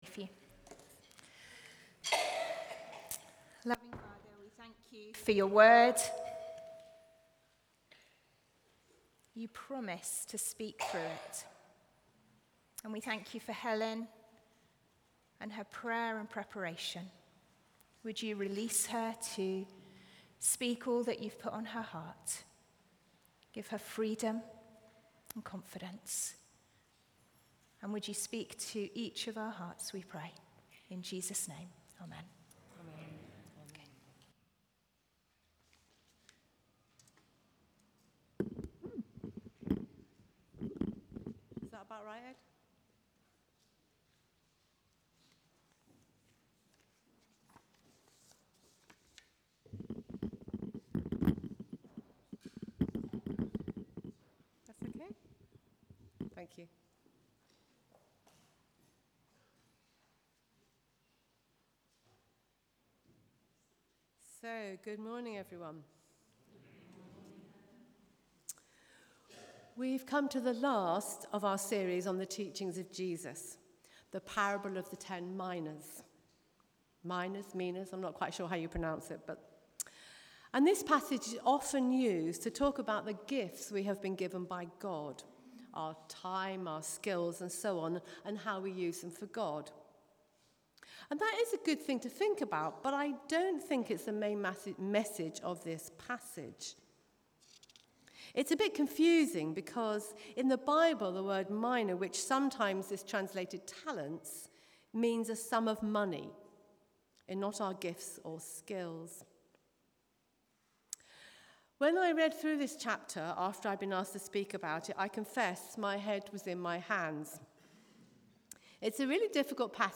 Media for Sunday Service on Sun 20th Jul 2025 10:30
Theme: Parable of the Talents Sermon